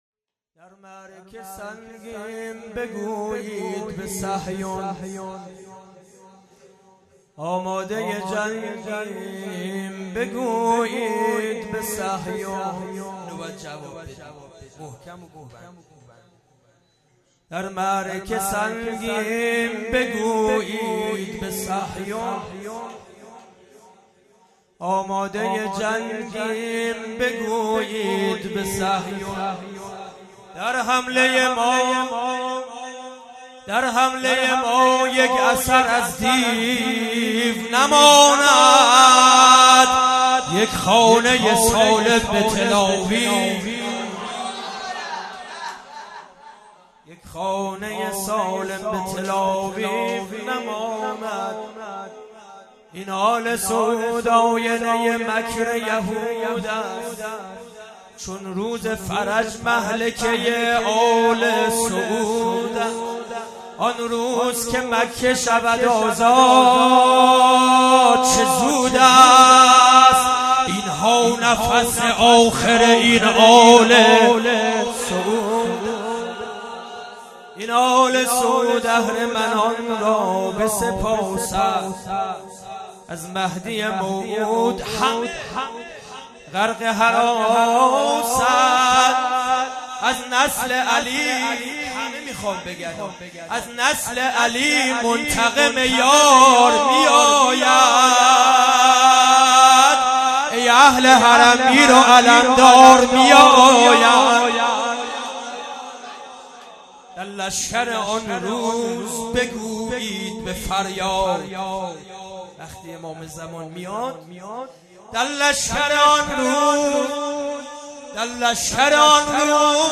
خیمه گاه - هیئت بچه های فاطمه (س) - شعر خوانی | در معرکه سنگیم بگویید به صهیون
جلسۀ هفتگی